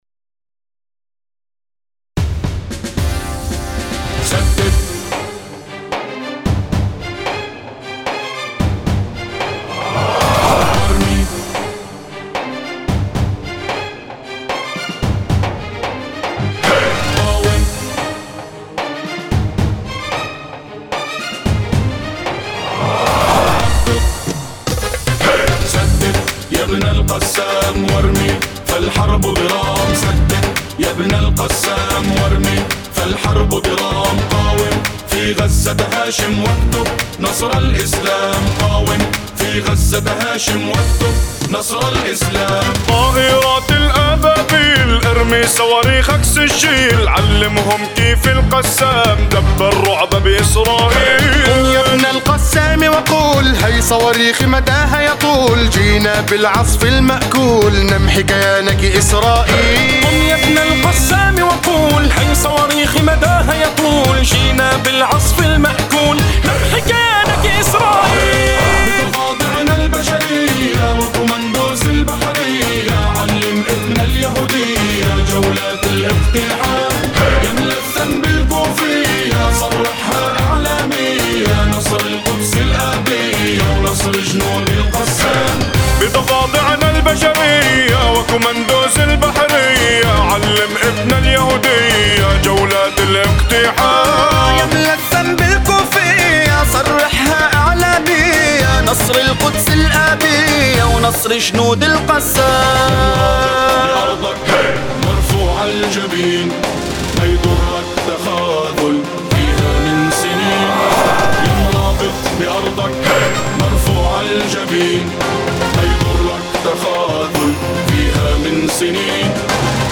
أناشيد فلسطينية... العصف المأكول